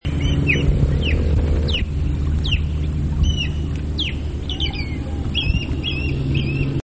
Rybołów - Pandion haliaetus
głosy